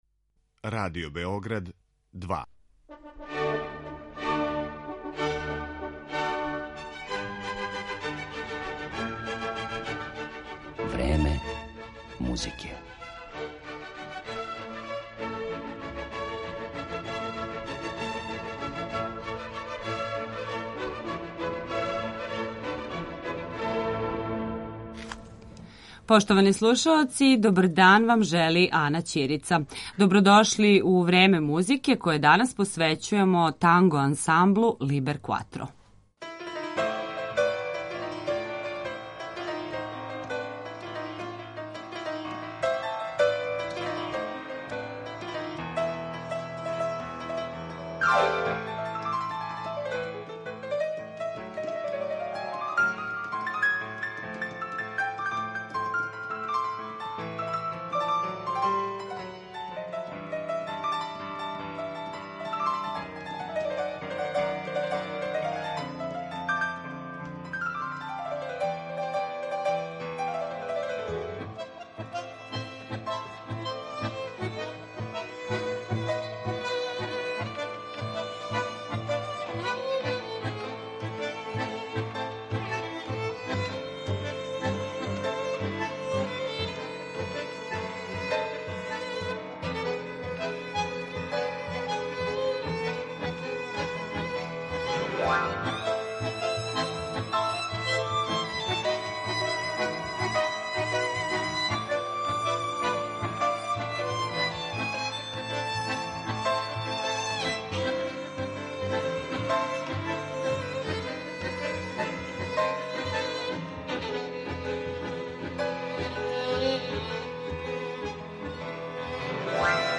Танго ансамбл Libercuatro
Ансамбл карактерише висок извођачки ниво, изузетна енергичност, као и специфична интерпретација.